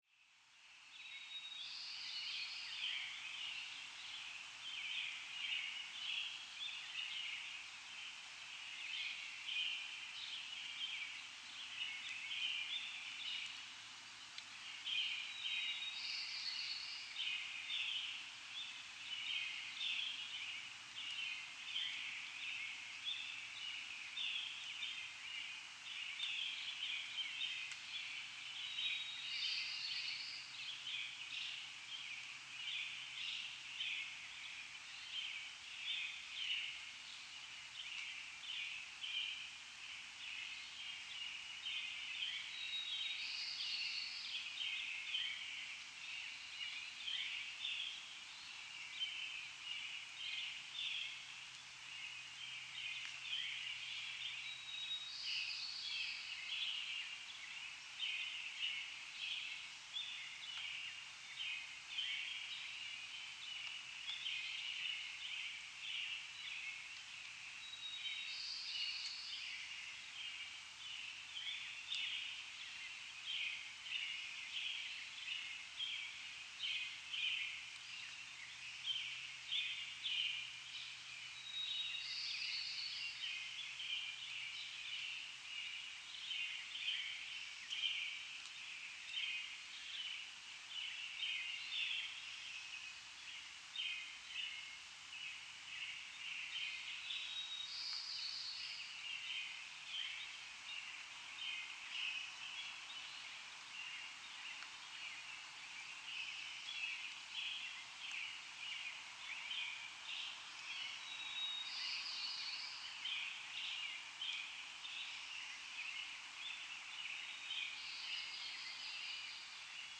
Lopez-Island-Dawn-Chorus.mp3